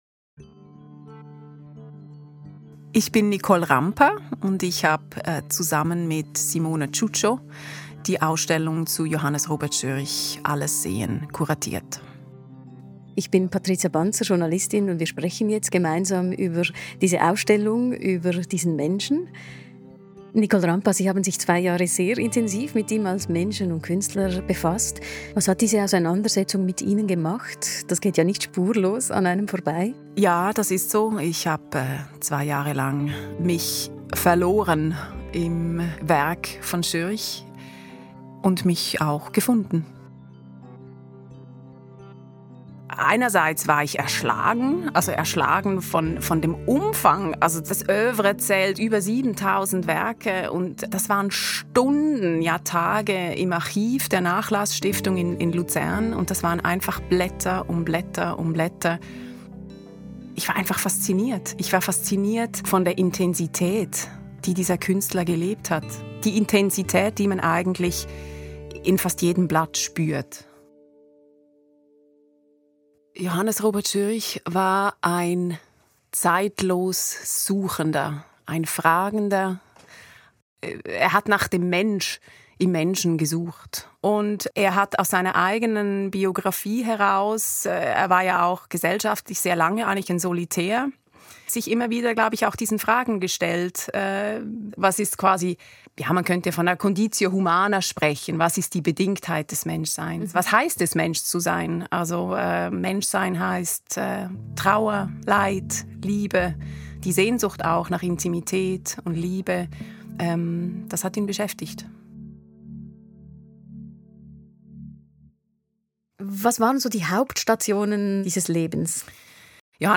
She talks about the research work and looks behind the ‘veil of misery’ of his works. Listen to the conversation